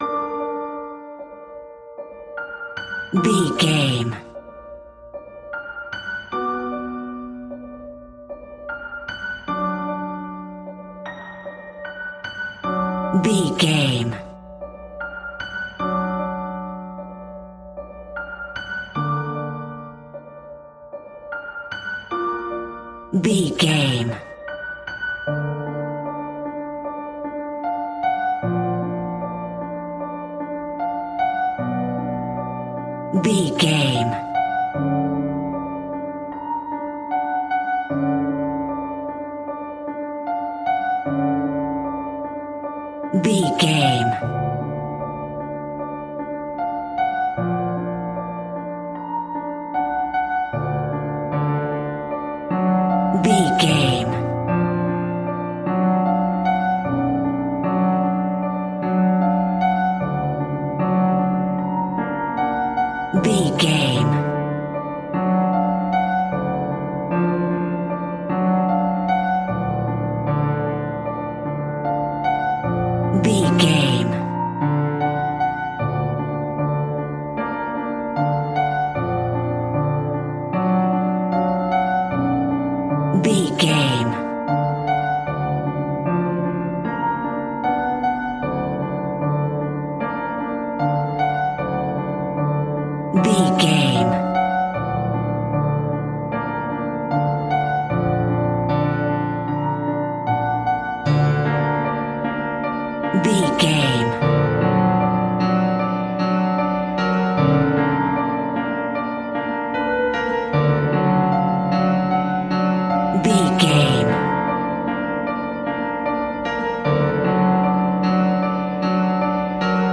Diminished
tension
ominous
suspense
haunting
eerie
horror
Acoustic Piano